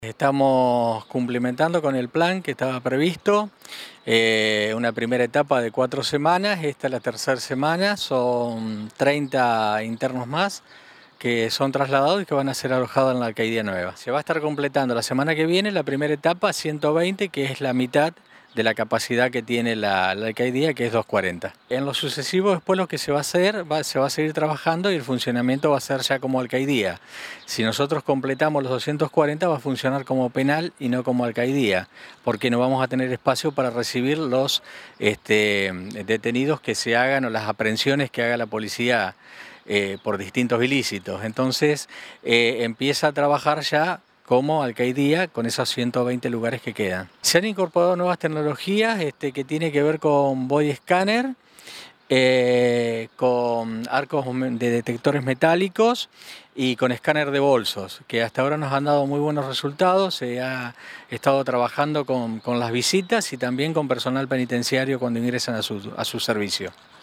Director del Servicio Penitenciario, Gabriel Leegstra.